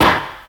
• 00s Damped Disco Steel Snare Drum Sound F# Key 470.wav
Royality free snare drum sample tuned to the F# note. Loudest frequency: 1664Hz
00s-damped-disco-steel-snare-drum-sound-f-sharp-key-470-vhW.wav